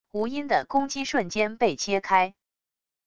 无音的攻击瞬间被切开wav音频